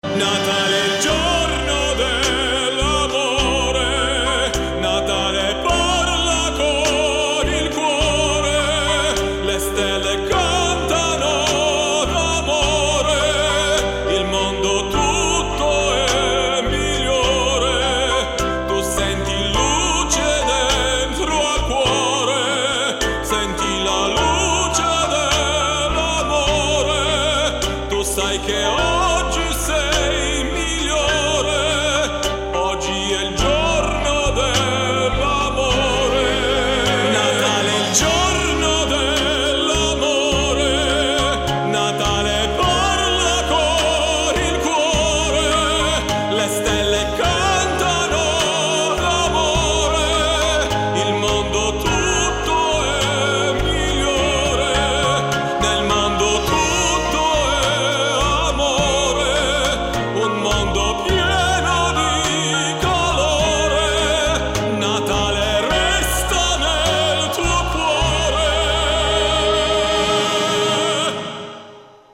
• Качество: 224, Stereo